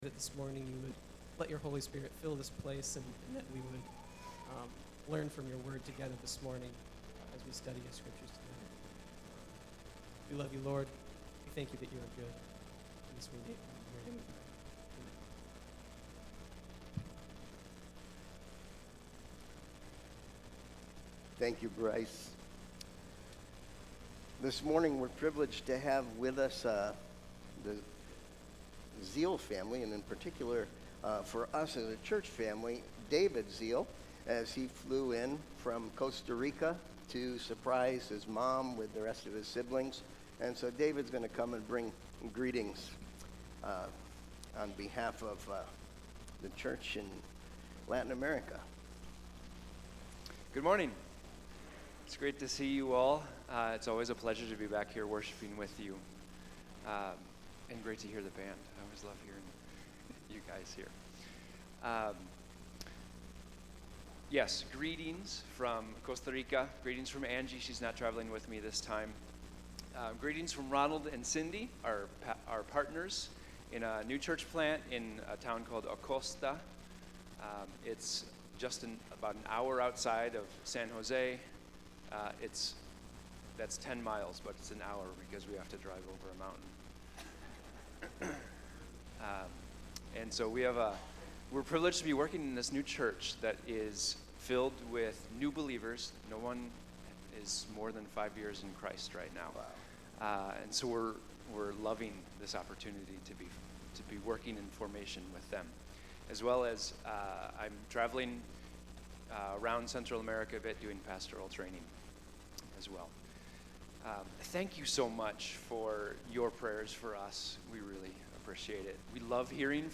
Sermon Question: What is the basis of your acceptance by God?